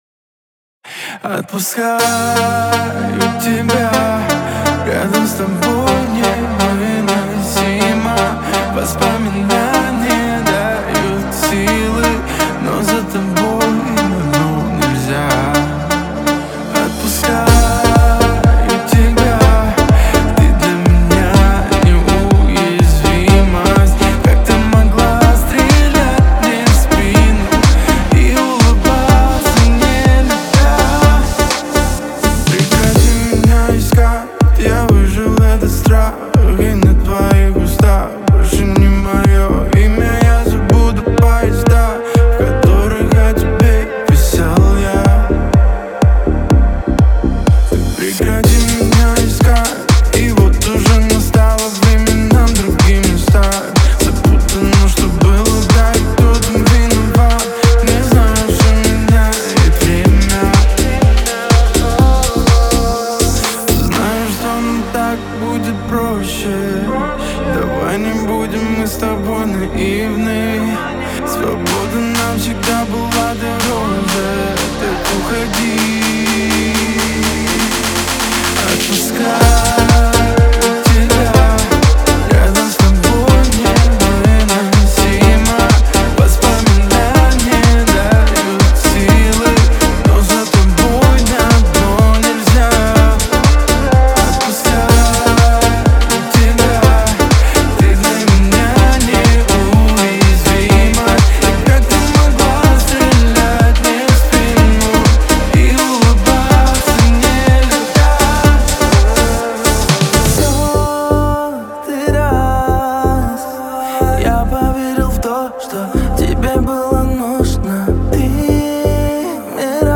зажигательная поп-музыка